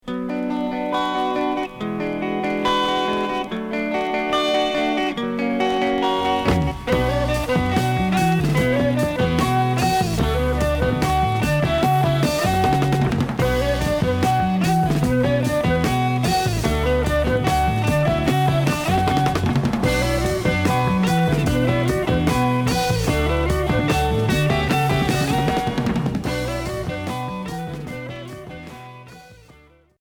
Pop Unique 45t retour à l'accueil